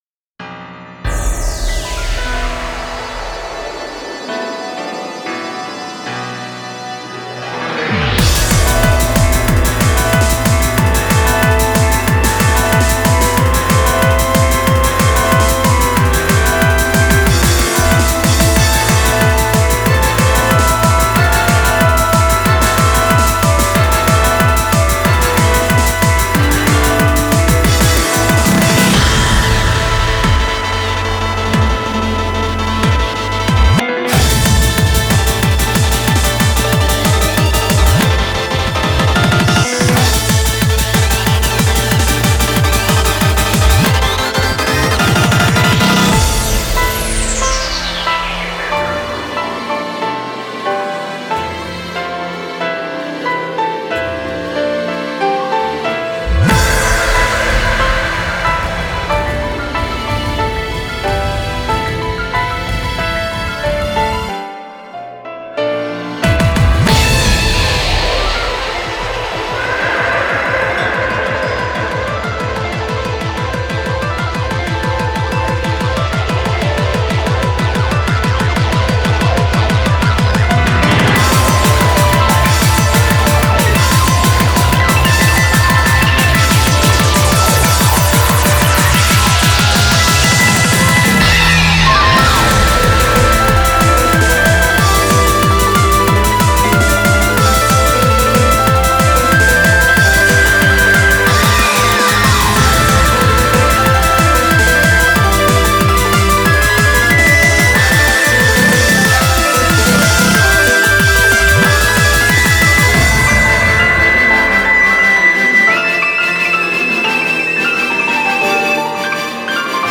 BPM93-185
Audio QualityPerfect (High Quality)
Genre: ESOTERIC SPEEDCORE.